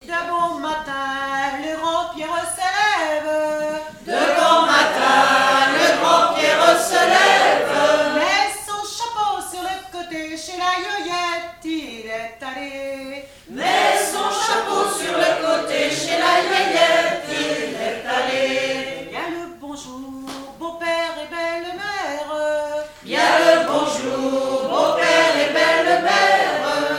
Genre laisse
7e festival du chant traditionnel : Collectif-veillée
Pièce musicale inédite